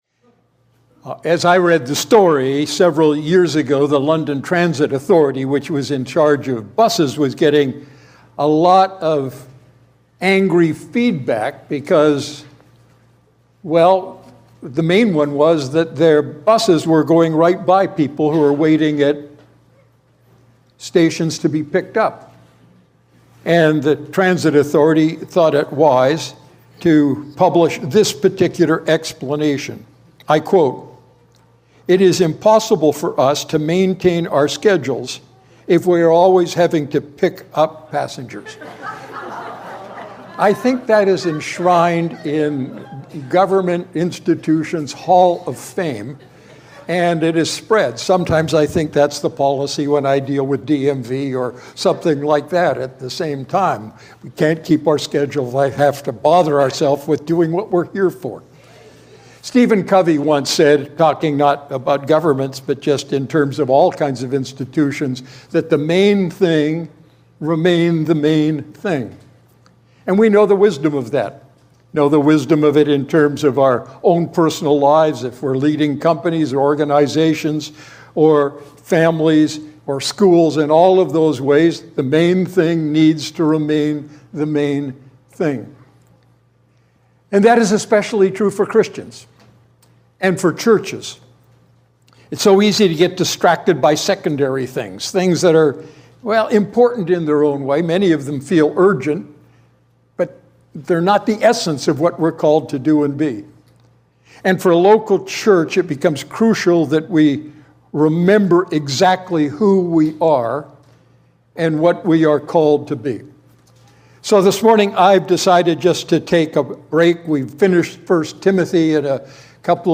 Sermon Archive, Redeemer Fellowship